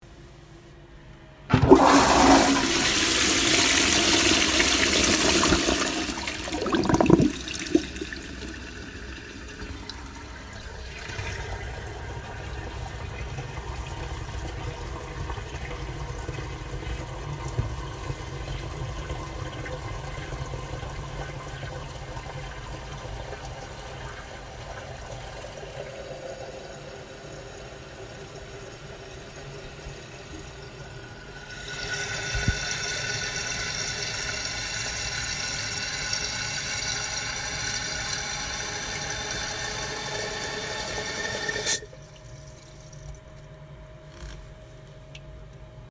冲洗马桶
描述：冲洗厕所
声道立体声